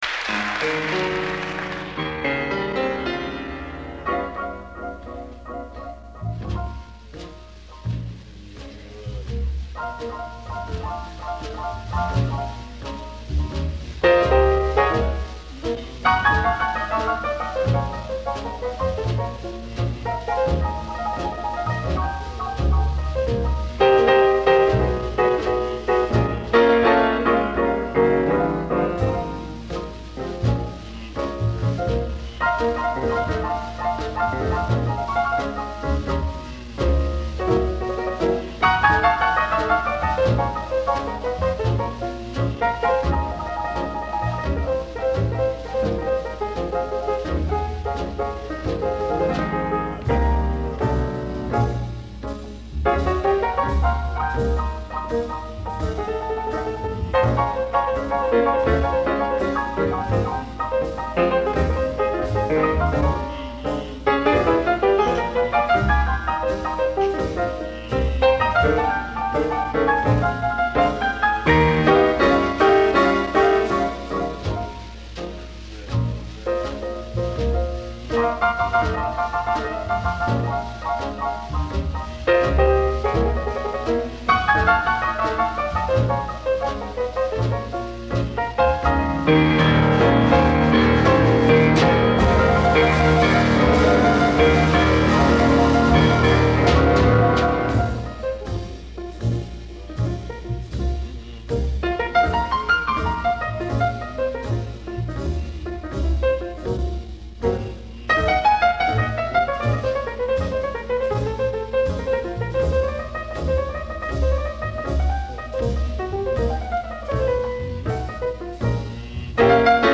Digitally remastered directly from original analog tapes.
Recorded in actual performance at Carmel, California
piano
bass
drums
September 19, 1955, live in Carmel, California